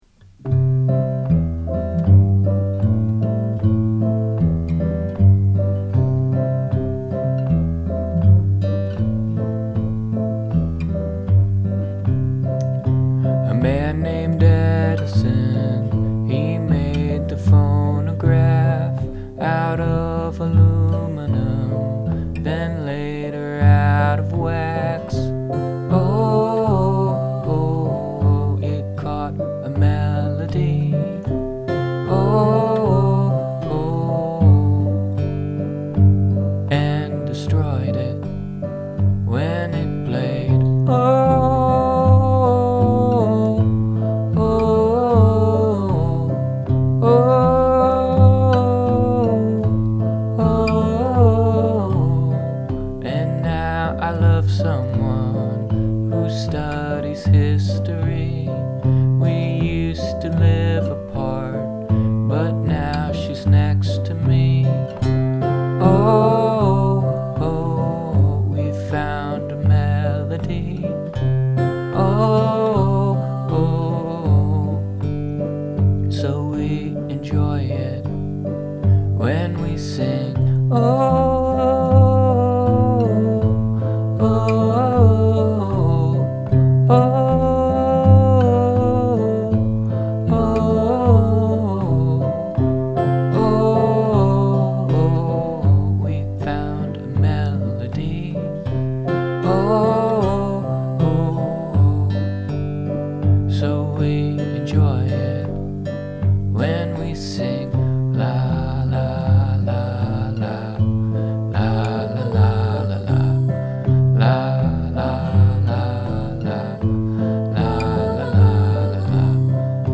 It's in C major.
verse, pre chorus, chorus, verse, pre chorus, chorus, pre chorus, chorus
i like this one a lot, a little low-key, but the recording is nice (that new setup must be boss). i like the prechorus part of this best, partly because of the syncopation in the melody over the straight backing rhythm. i like the keys on the offbeats, too. it helps the tune rock along pleasantly. well done for a dashed-off number. can't wait for next month!